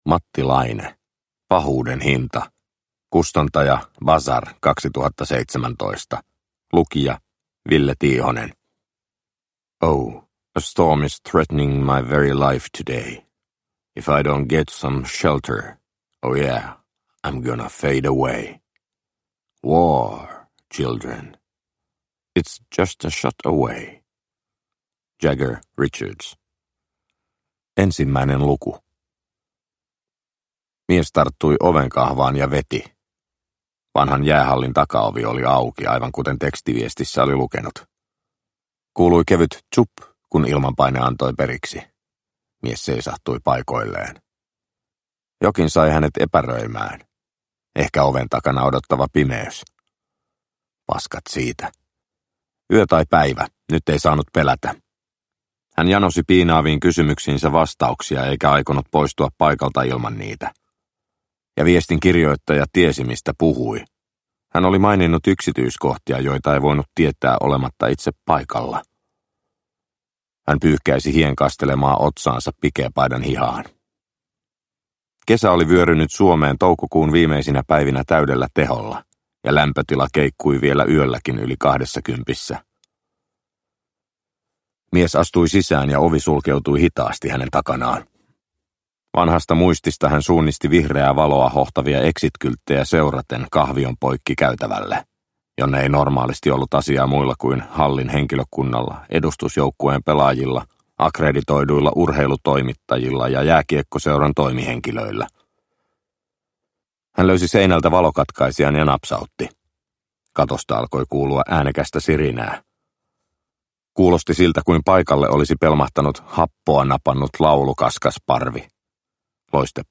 Pahuuden hinta – Ljudbok – Laddas ner